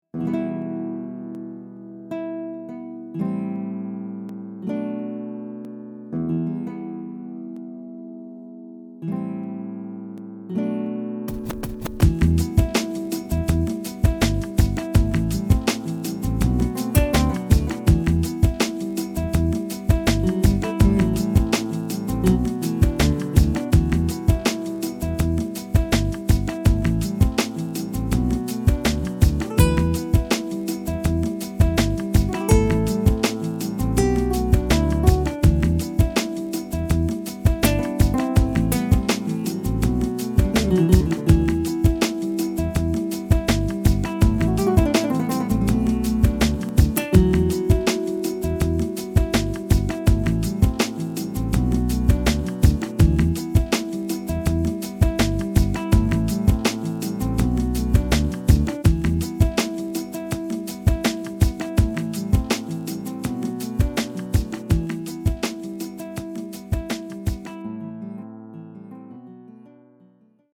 Listen  FREE Classic - Nylon guitar